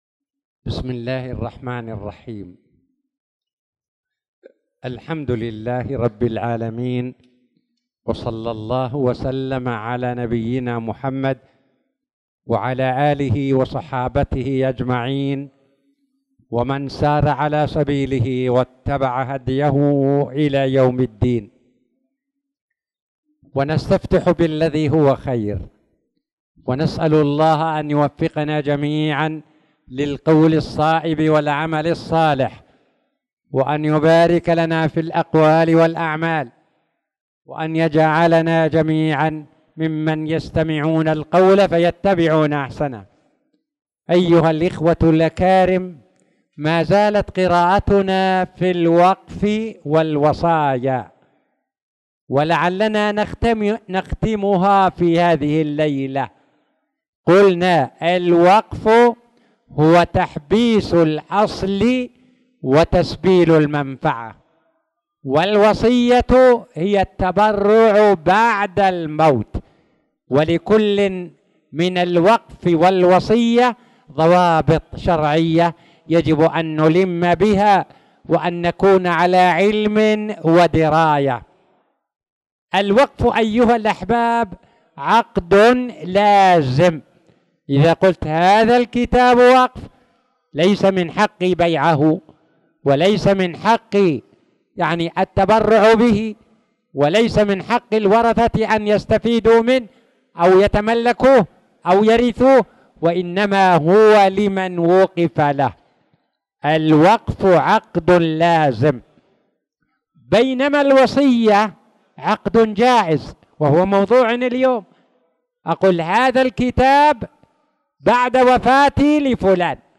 تاريخ النشر ١٨ شعبان ١٤٣٧ هـ المكان: المسجد الحرام الشيخ